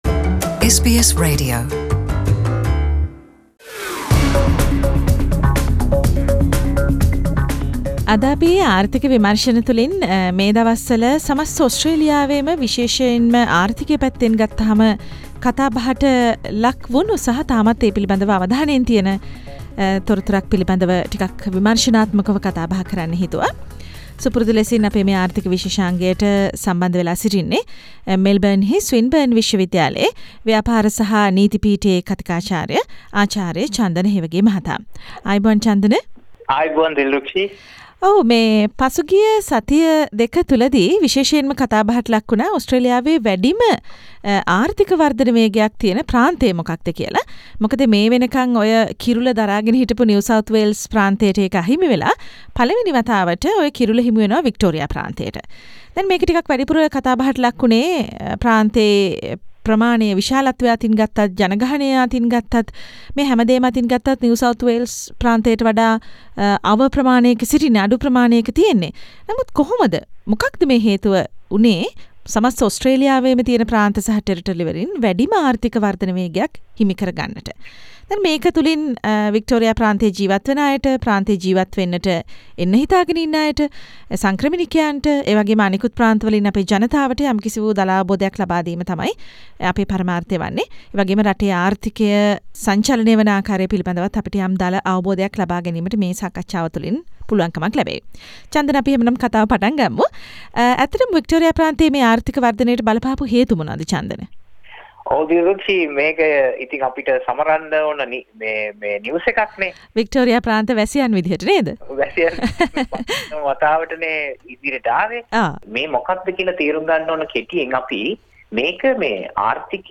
එයට බලපෑ සාධක සහ එය ප්‍රාන්තයේ සහ රටේ ආර්ථිකයට බලපානුයේ කෙසේද යන්නත් සංක්‍රමණික ප්‍රජාවනට එය අදාල වන අයුරුත් මෙම සාකච්චාවෙන් විග්‍රහයට ලක්වෙයි.